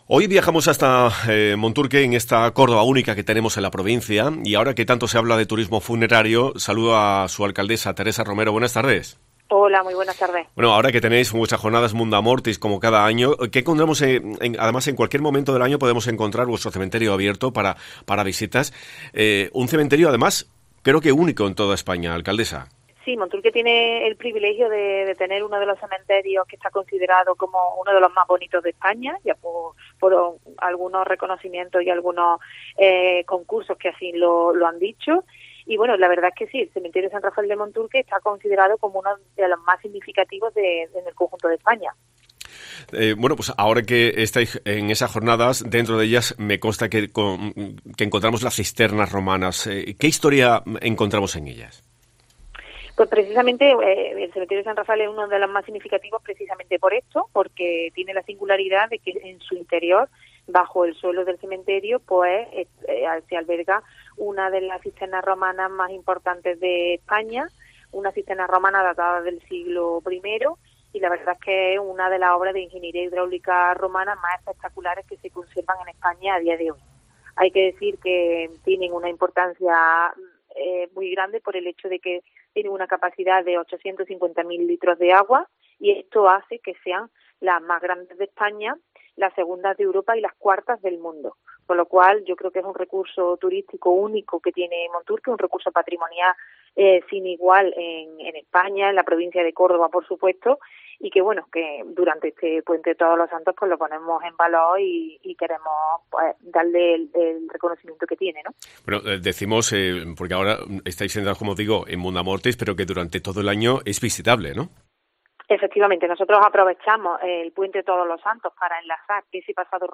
Hoy hemos hablado en COPE con su alcaldesa, María Teresa Romero, que ha destacado que "durante todo el año son muchas las personas que nos visitan para ver nuestro cementerio y la historia que encontramos en él".